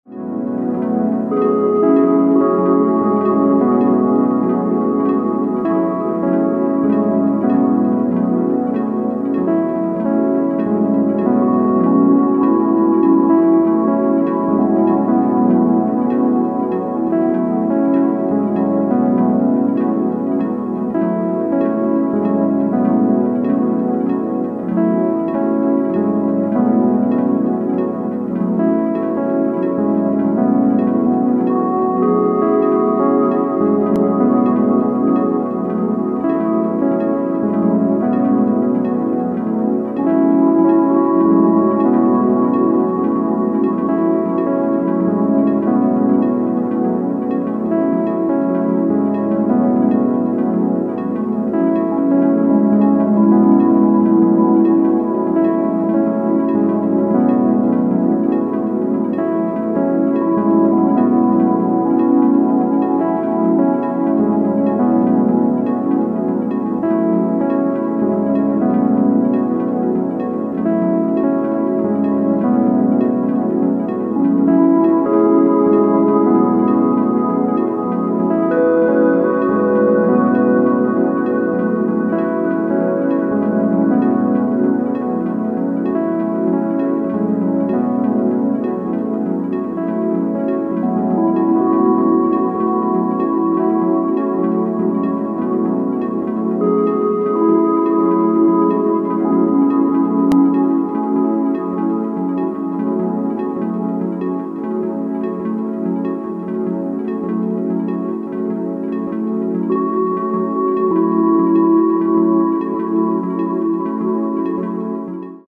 徹底的にミニマルに、そして美しいテクスチャーのみを追求した完全なる2CDアンビエント・アルバム
全15曲、大きな抑揚や山のある展開は抑え、スタートからほぼ同一のテンションが続く流れを維持。